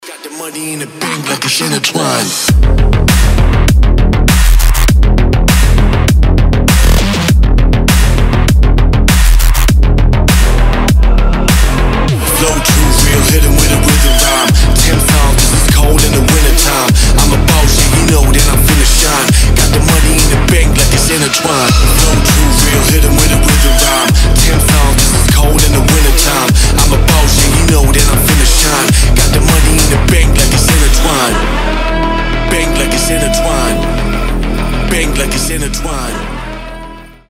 громкие
жесткие
мощные басы
Midtempo
midtechno
качающие
взрывные
G-House
Gangsta
мужские
Сирена
эпичные